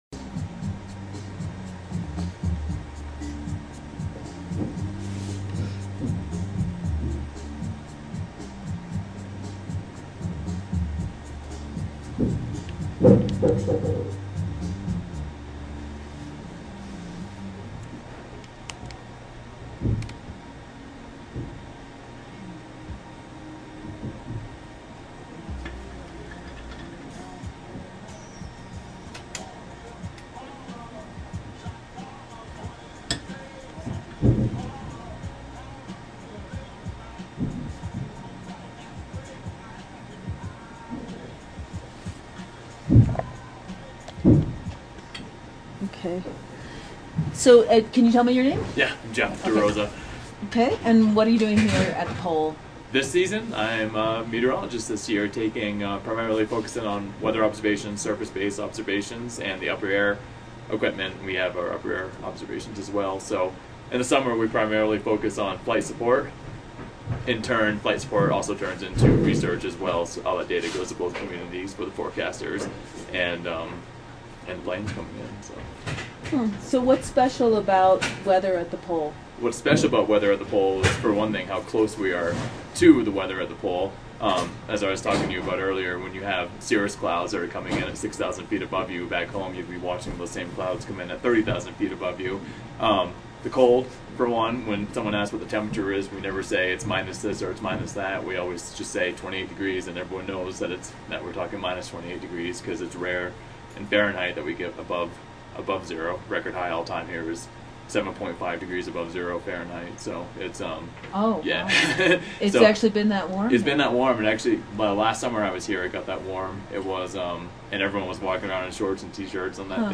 Weather_Observers_Interview.mp3